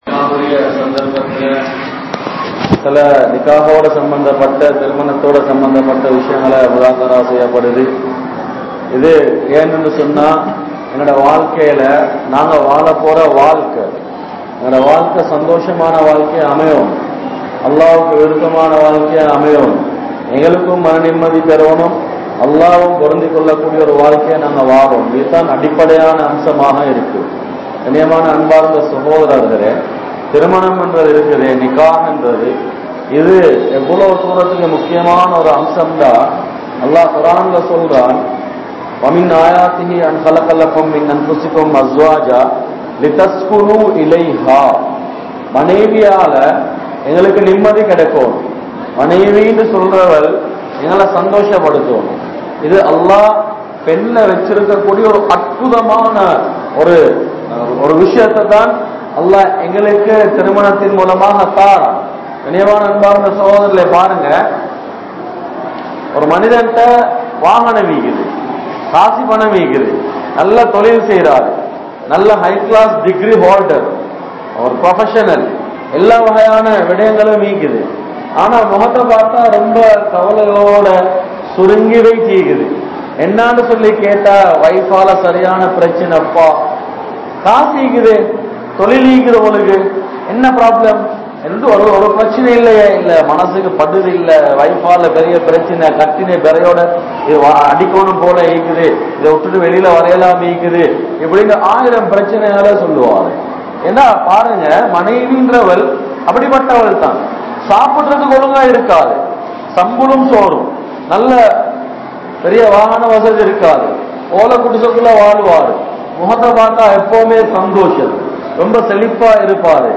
Paavangalai Thadukkum Nikkah (பாவங்களை தடுக்கும் நிக்காஹ்) | Audio Bayans | All Ceylon Muslim Youth Community | Addalaichenai